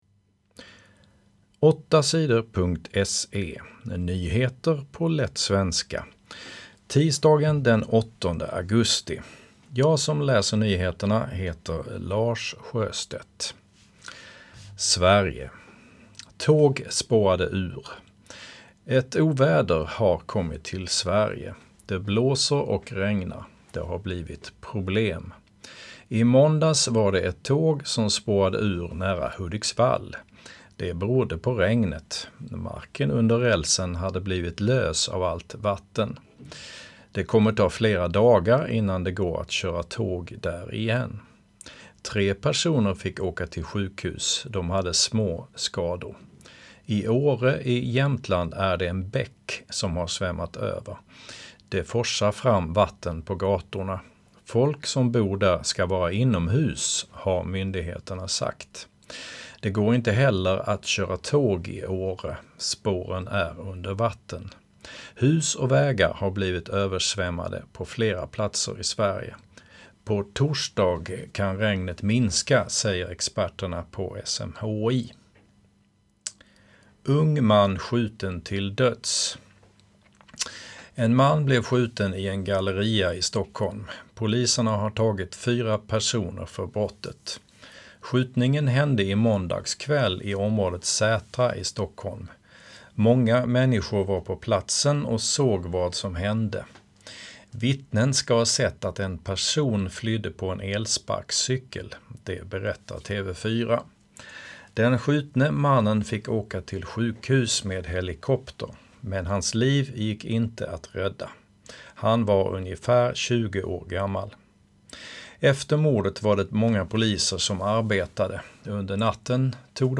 Nyheter på lätt svenska den 8 augusti